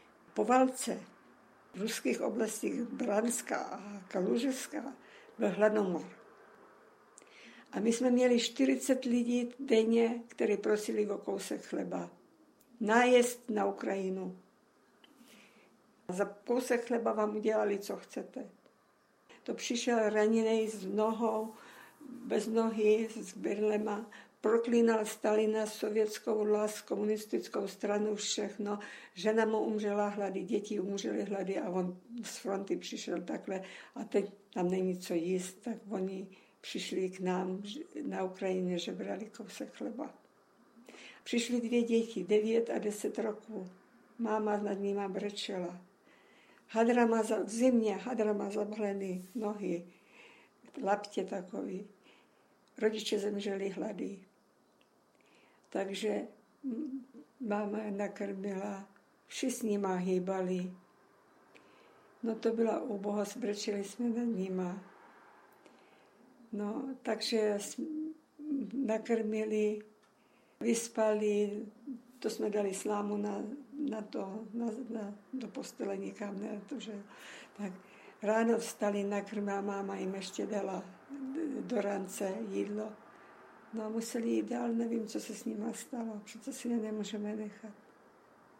Rozhovory-Post Bellum © - Paměť národa